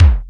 Index of /kb6/Korg_05R-W/Korg Kicks
Kick Syn 02 X5.wav